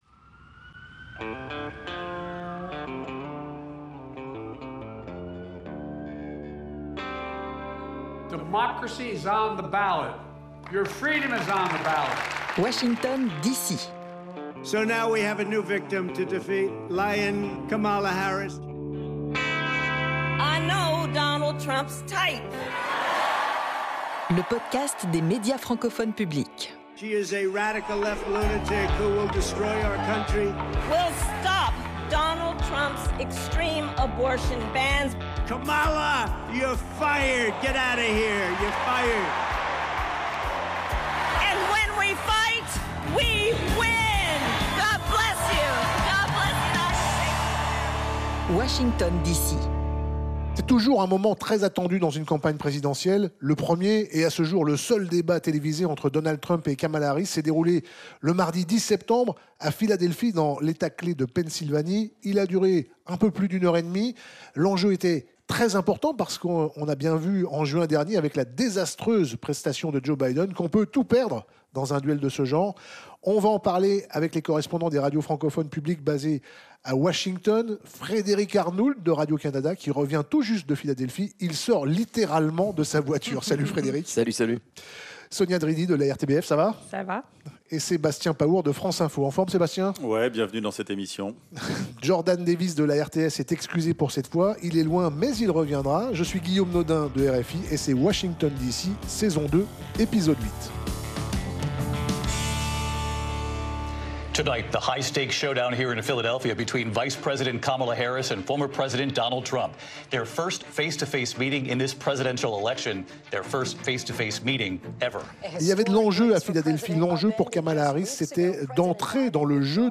L'analyse des correspondants des Médias francophones publics (MFP) dans le 8e épisode de cette saison 2 de Washington d'ici.